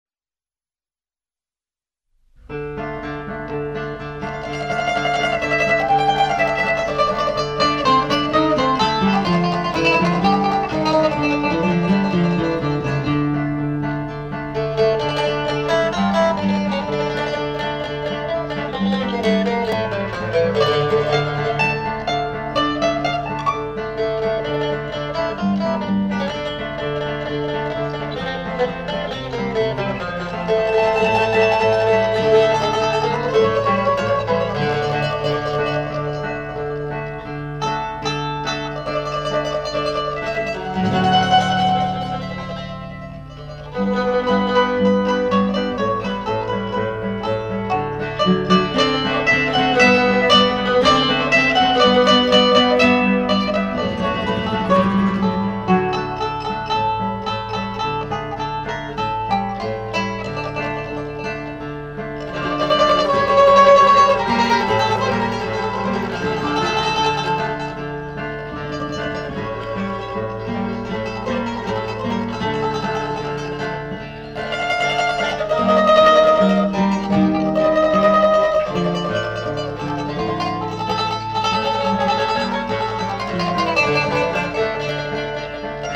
• Теги: минусовка